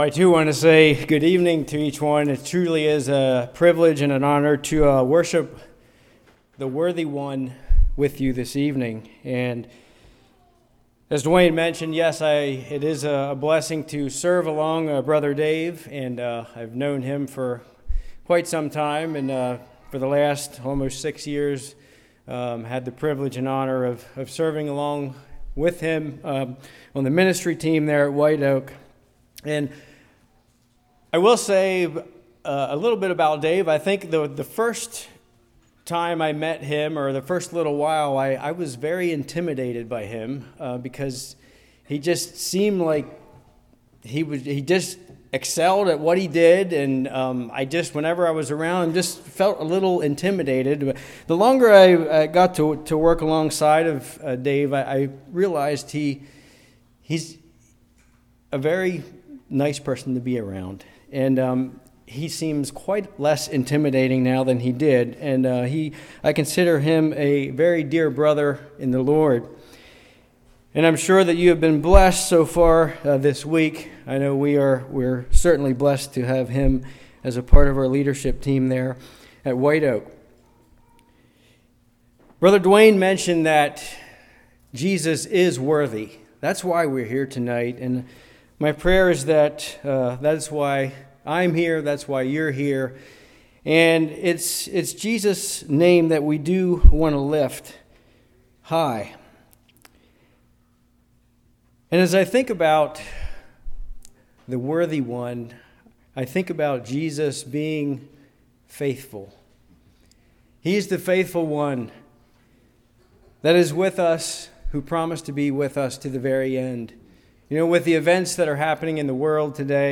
Matthew 7:13-27 Service Type: Revival We